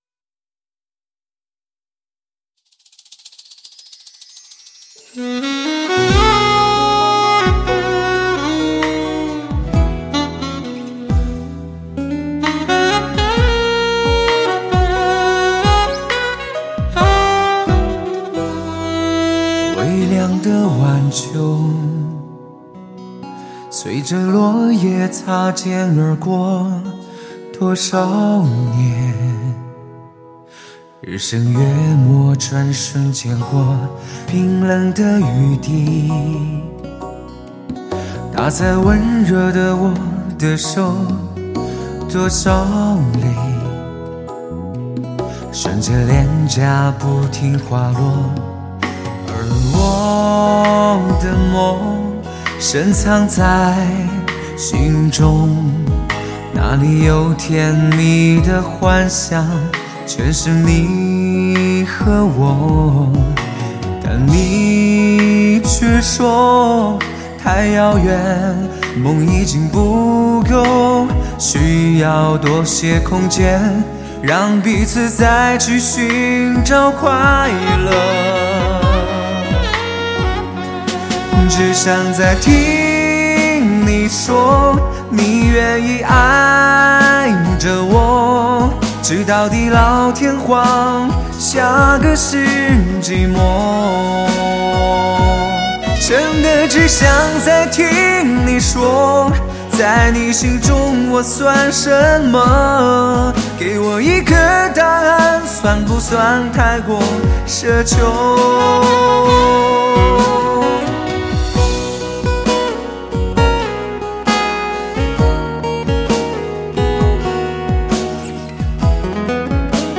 强烈的质感，在男声作品中实属罕见的完美声音，非一般可媲美。